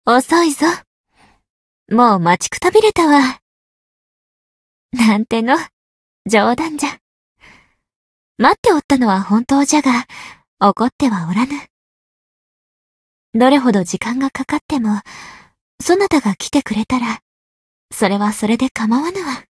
灵魂潮汐-蕖灵-七夕（送礼语音）.ogg